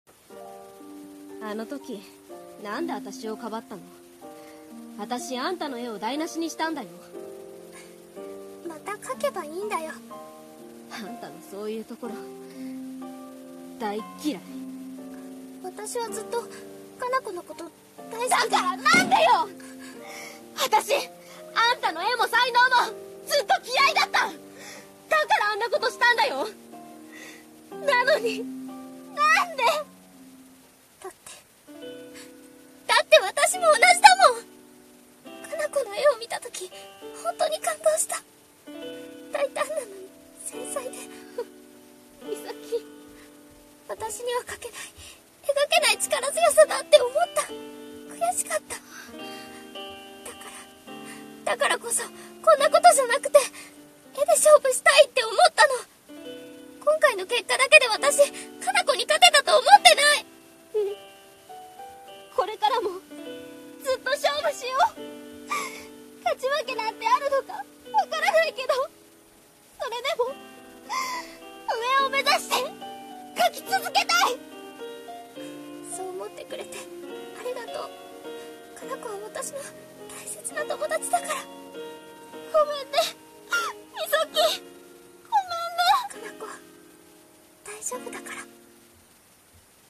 【コラボ声劇】私たちはライバル。